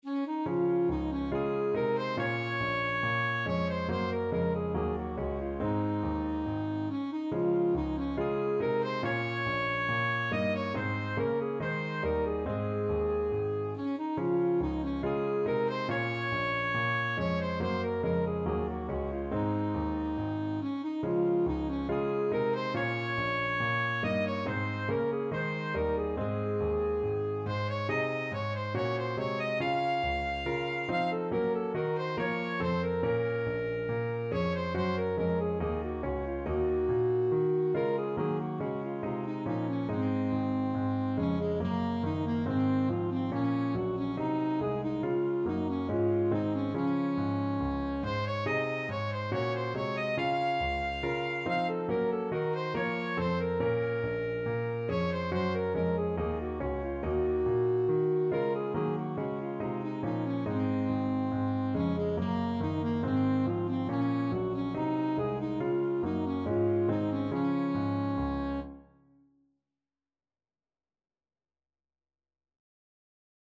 Alto Saxophone
Db major (Sounding Pitch) Bb major (Alto Saxophone in Eb) (View more Db major Music for Saxophone )
handel_gavotte_hwv491_ASAX.mp3